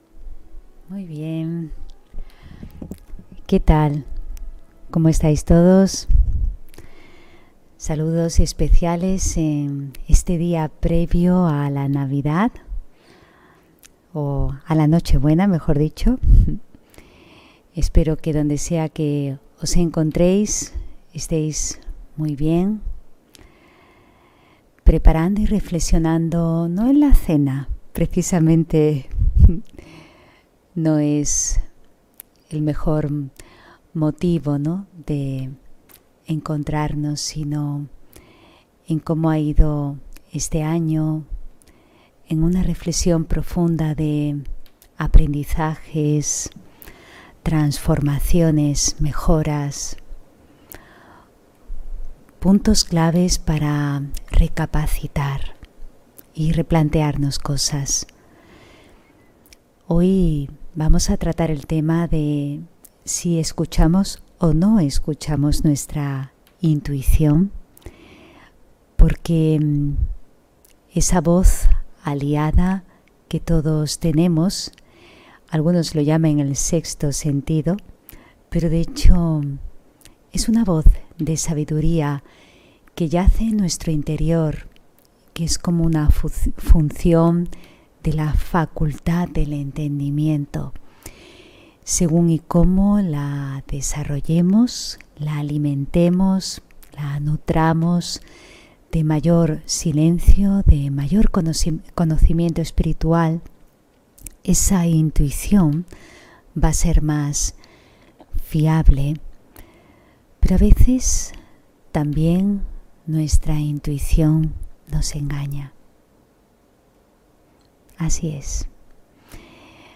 Meditación Raja Yoga y charla ¿Escucho mi intuición? (23 Diciembre 2020) On-line desde Sevilla